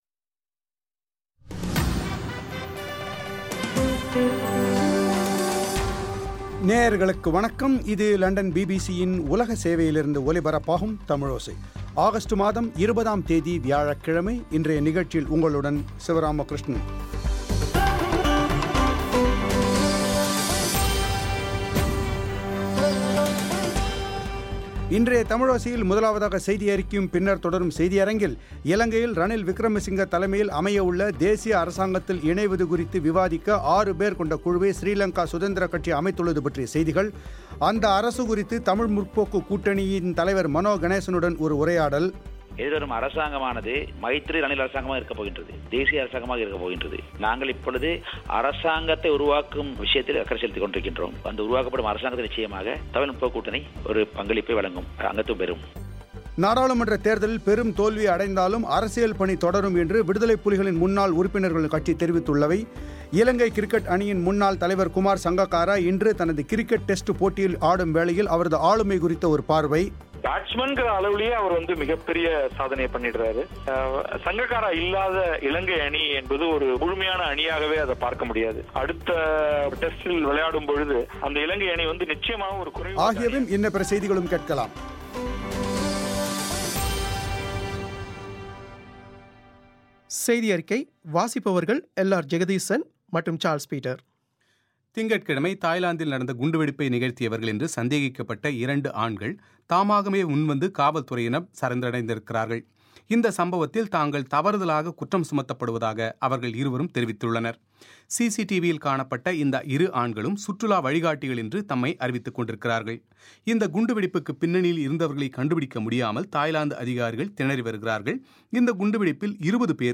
அந்த அரசு குறித்து தமிழ் முற்போக்கு கூட்டணியின் தலைவர் மனோ கணேசனுடன் ஒரு உரையாடல்